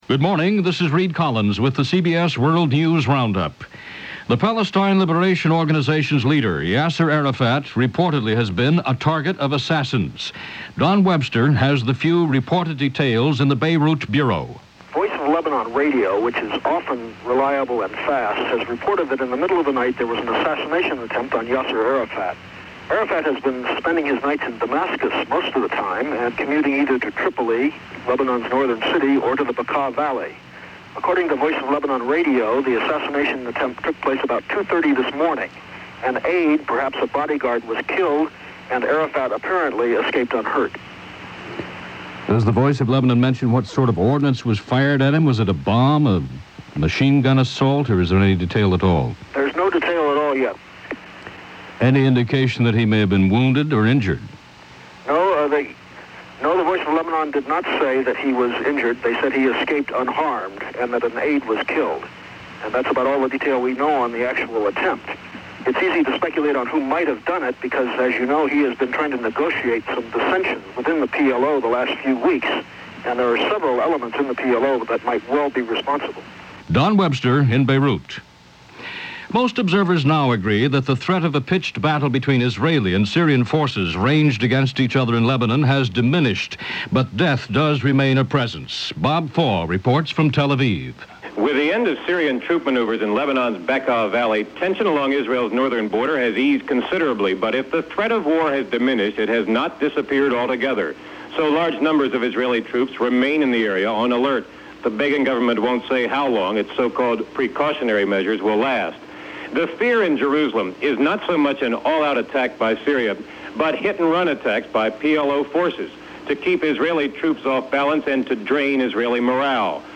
And along with news on Yasser Arafat, that’s just a little of what happened this May 30, 1983 as reported by The CBS World News Roundup.